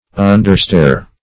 Search Result for " understair" : The Collaborative International Dictionary of English v.0.48: Understair \Un"der*stair`\, a. Of or pertaining to the kitchen, or the servants' quarters; hence, subordinate; menial.